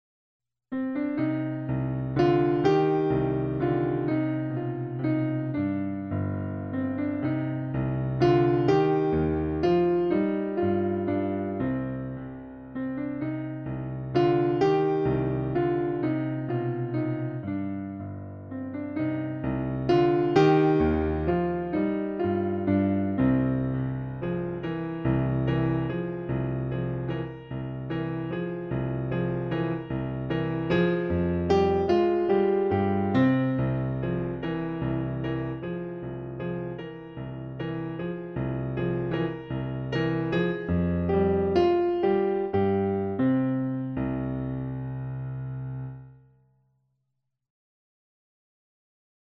描述：古典乐|悲伤
Tag: 钢琴